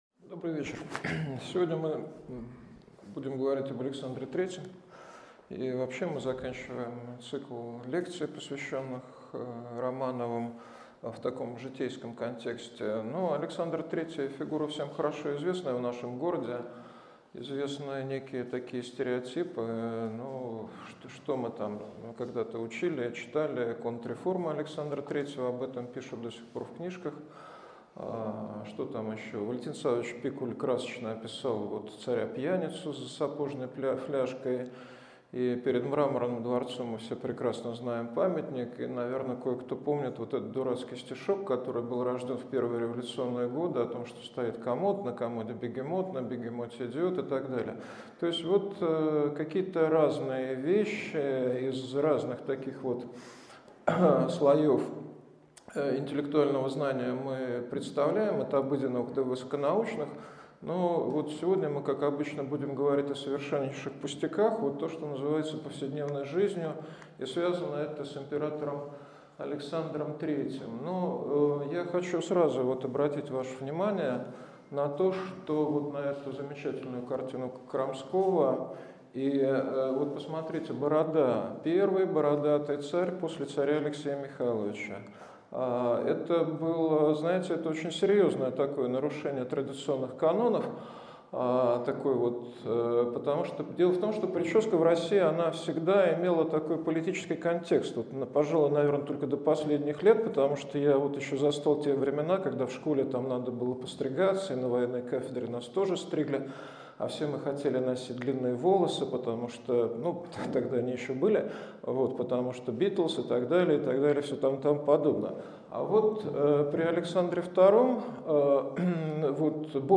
Аудиокнига Цари как люди. Александр III | Библиотека аудиокниг